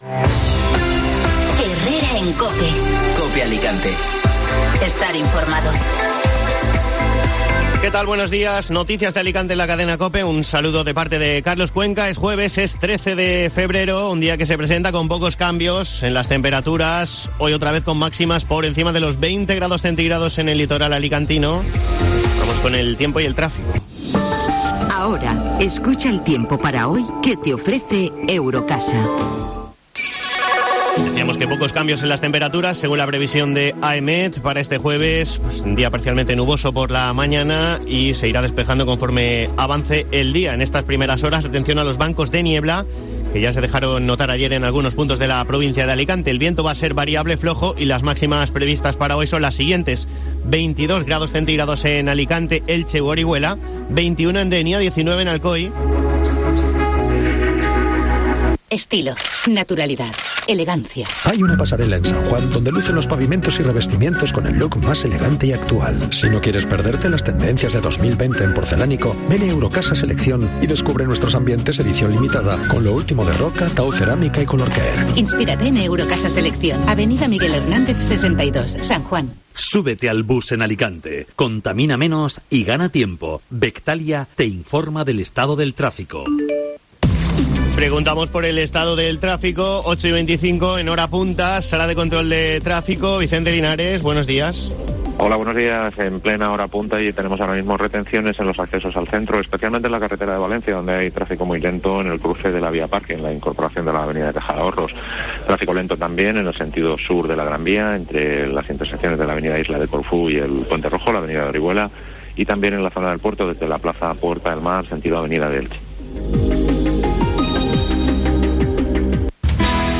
Informativo matinal (jueves 13 de febrero)